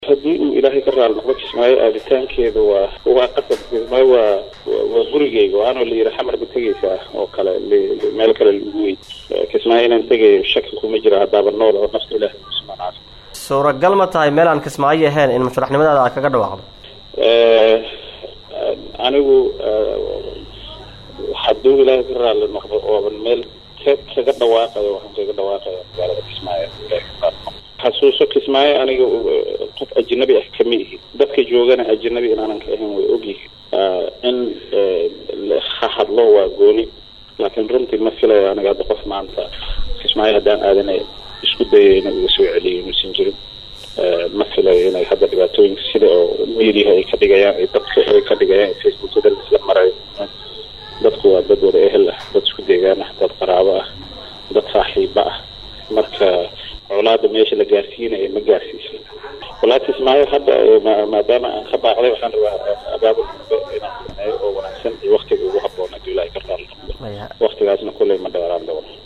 Wareysi uu siiyay Idaacadda Risaala ee Magalada Muqdisho ayaa waxaa uu sheegay in aadi taanka Magalada Kismaayo ay tahay mid qasab ah oo shaki geli Karin, isla markaana aysan jiri Karin cid ka hor-istaageyso.
Hoos ka dhageyso codka wasiirka Oomaar